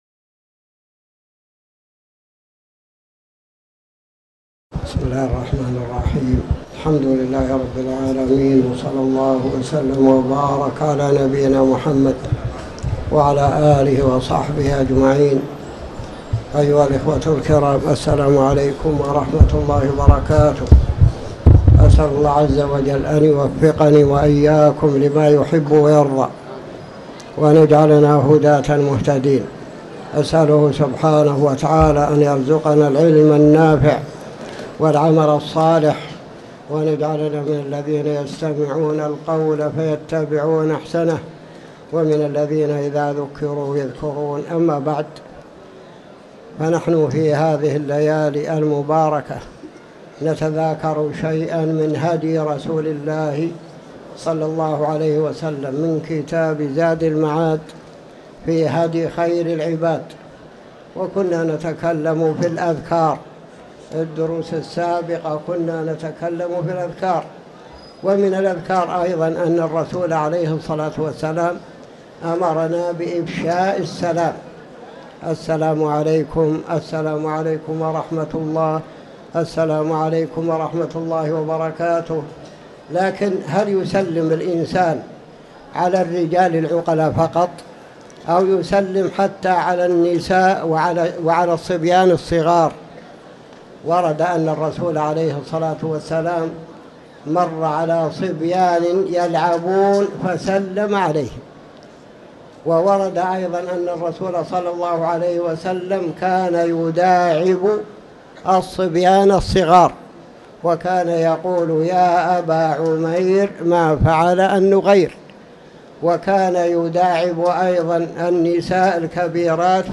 تاريخ النشر ١ جمادى الآخرة ١٤٤٠ هـ المكان: المسجد الحرام الشيخ